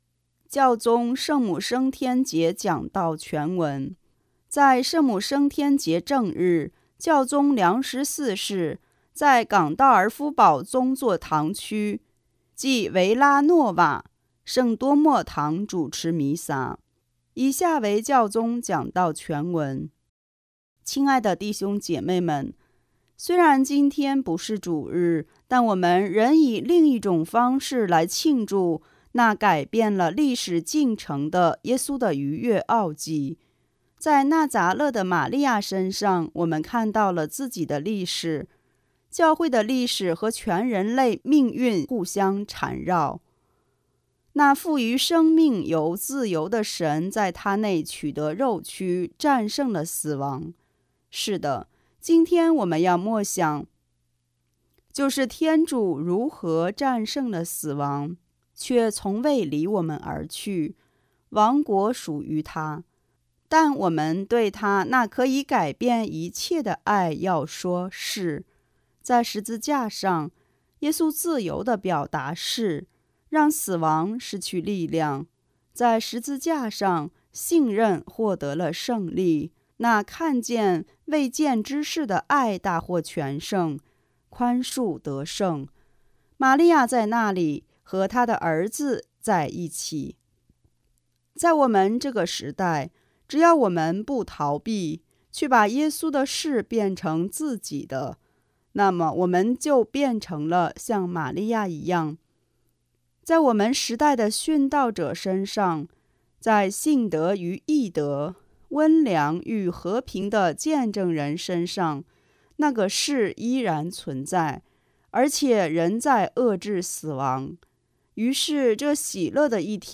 在圣母升天节正日，教宗良十四世在冈道尔夫堡宗座堂区，即维拉诺瓦圣多默堂主持弥撒。